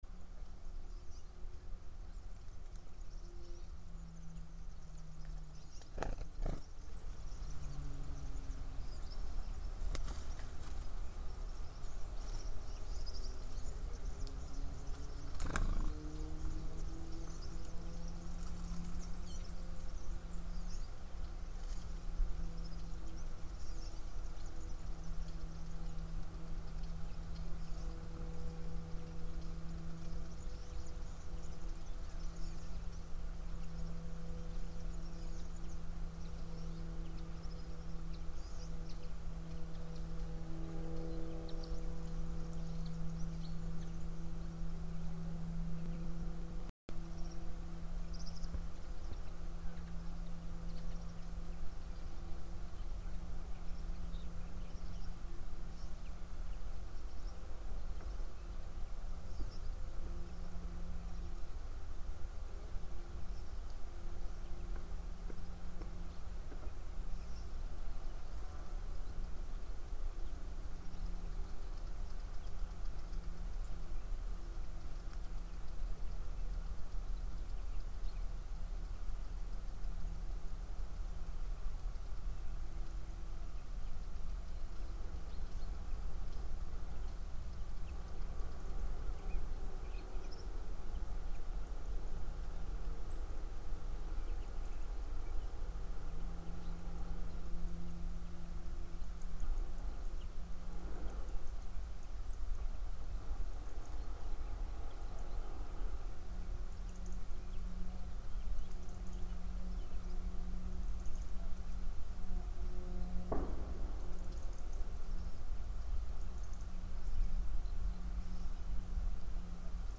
Live from Soundcamp: no name (Audio)